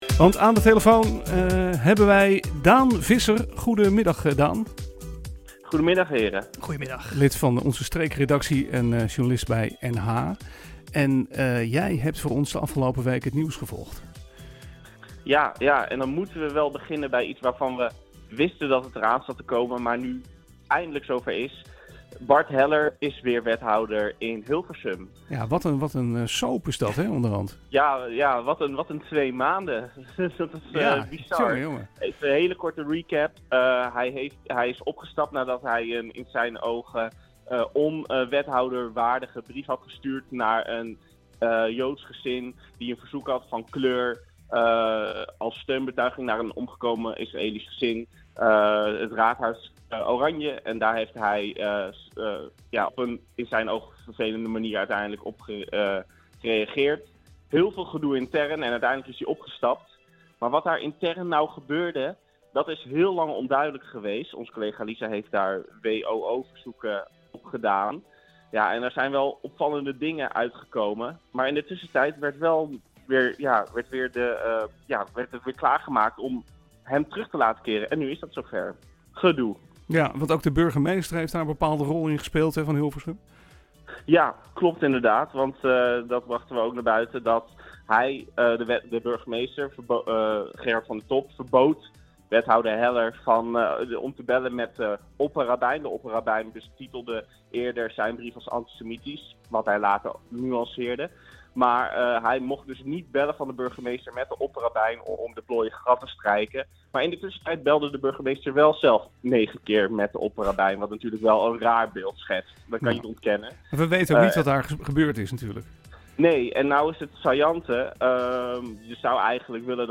aan de lijn om de highlights van de week door te nemen.